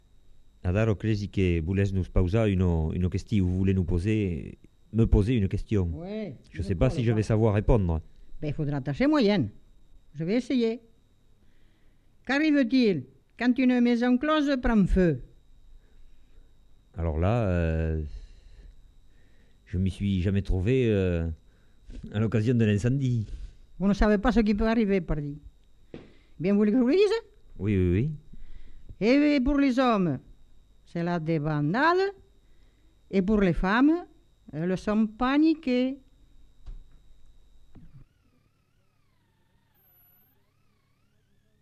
Lieu : Villeréal
Effectif : 1
Type de voix : voix de femme
Production du son : parlé
Classification : devinette-énigme